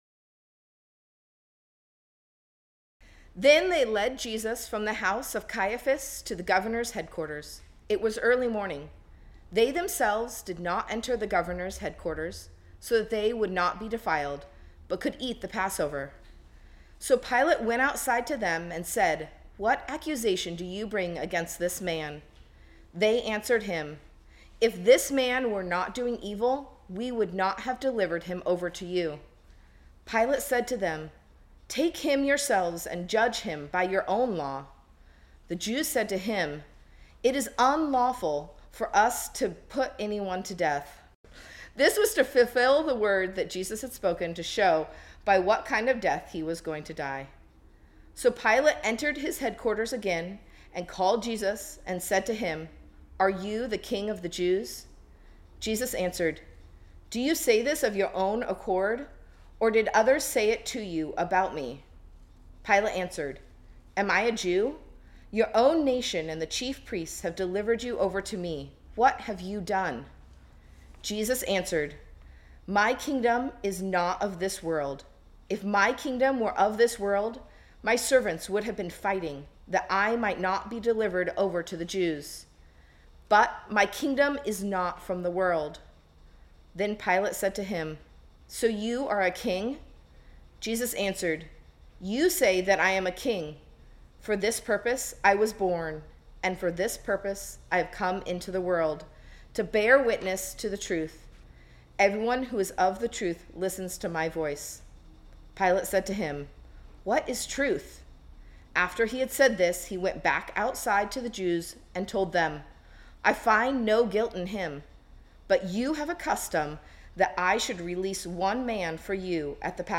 This sermon was originally preached on Sunday, July 19, 2020.